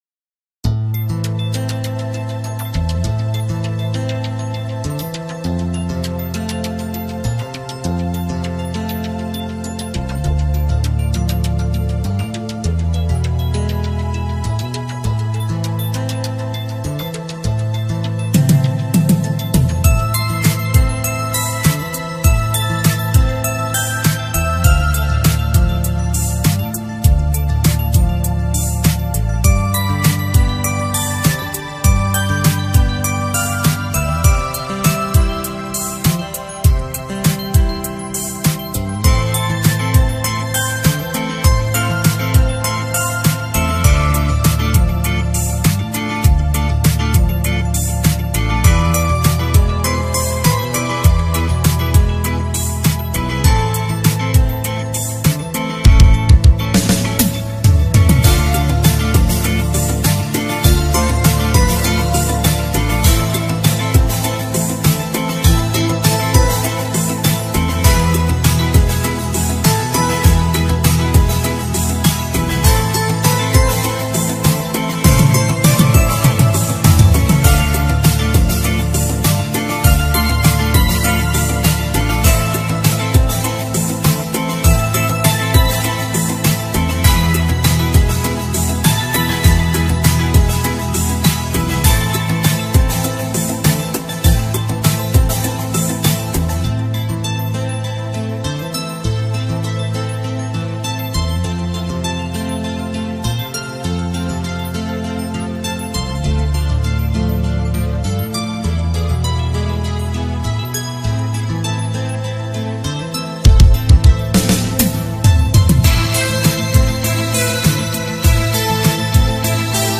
경음악